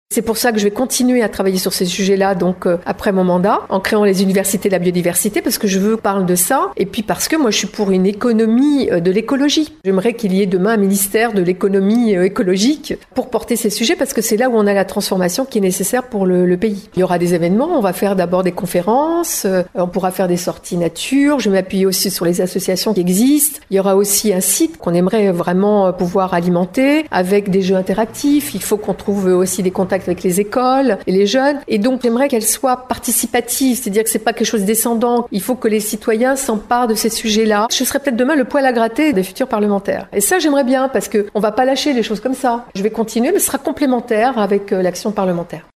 A quelques mois des élections législatives, la députée sortante de Charente-Maritime a tenu vendredi à sa permanence de Rochefort une conférence de presse de fin de mandat.